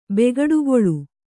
♪ begaḍugoḷu